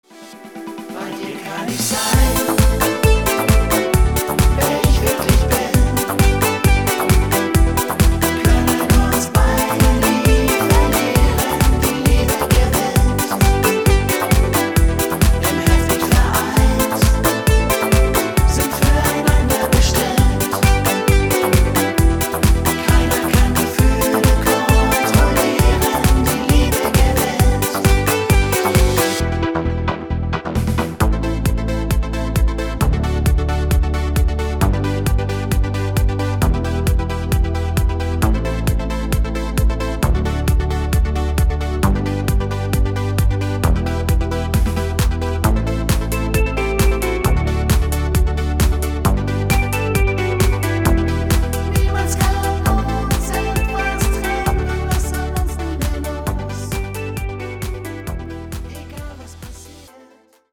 Rhythmus  8 Beat
Art  Deutsch, Duette, Party Hits, Schlager 2020er